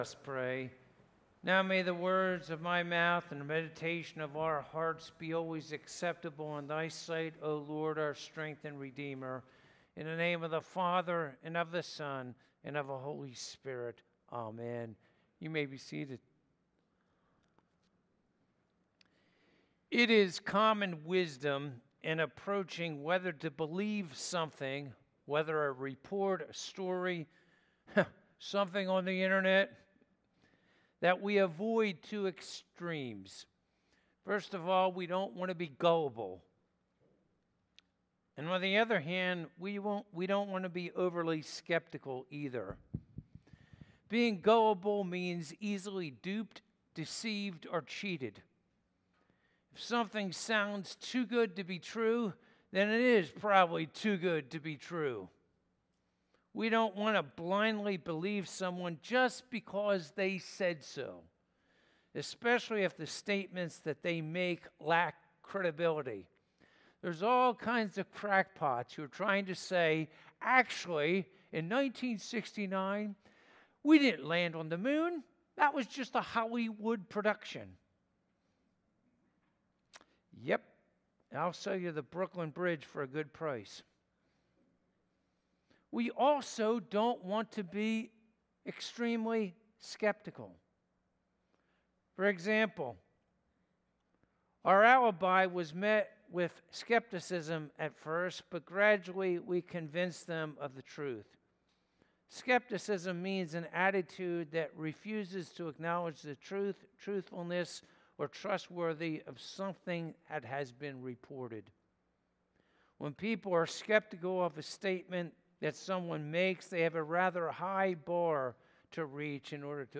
The Reality of Christ’s Resurrection (Easter Service)
Passage: Matthew 28:1-15 Service Type: Easter Sunday